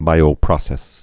(bīō-prŏsĕs, -prōsĕs)